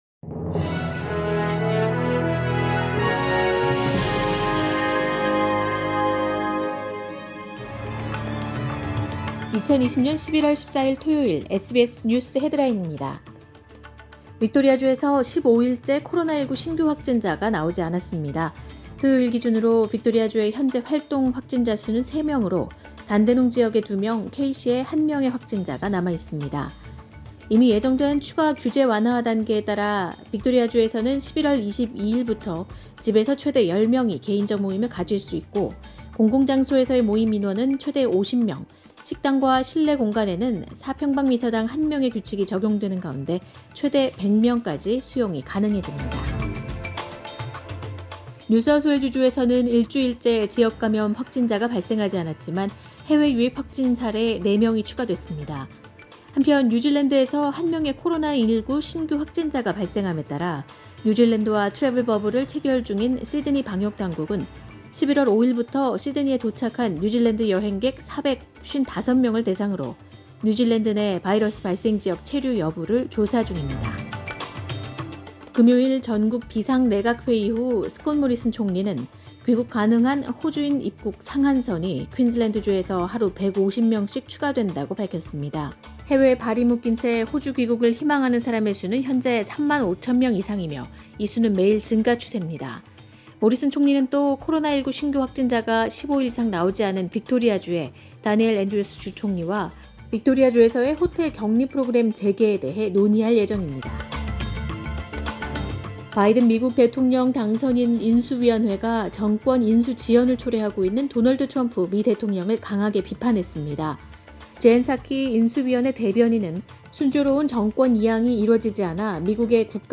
2020년 11월 14일 토요일 오전의 SBS 뉴스 헤드라인입니다.